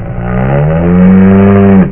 2_s_statek_krava.wav